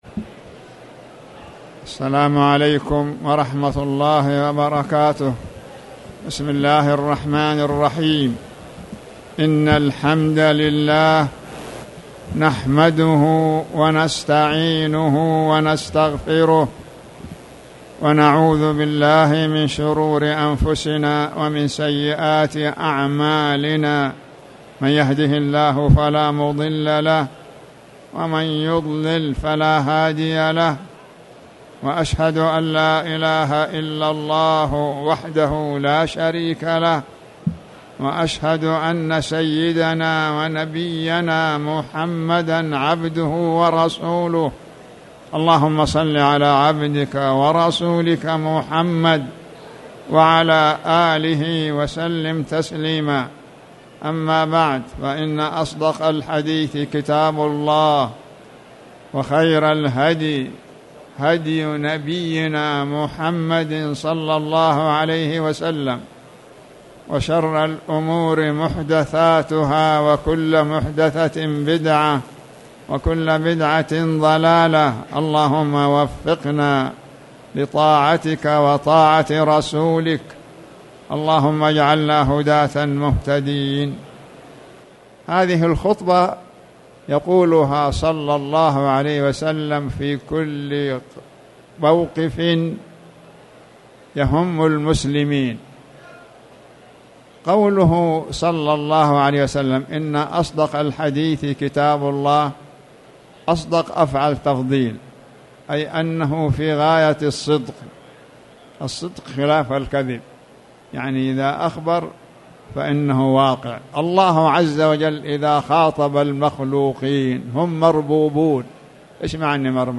تاريخ النشر ٩ شوال ١٤٣٨ هـ المكان: المسجد الحرام الشيخ